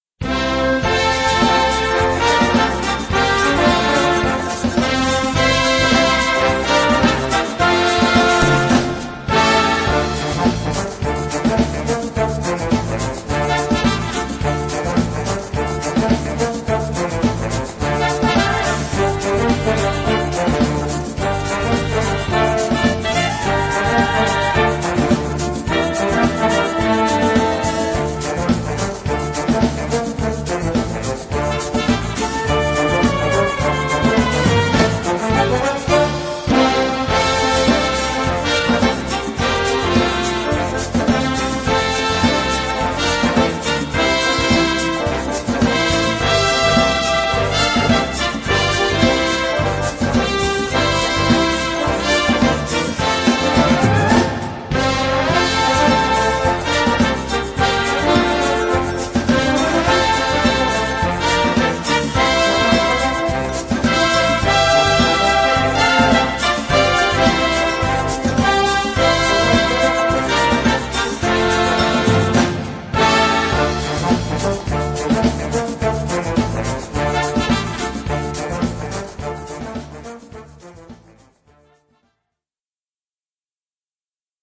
Gattung: Samba
Besetzung: Blasorchester
fetzige Komposition im lateinamerikanischen Stil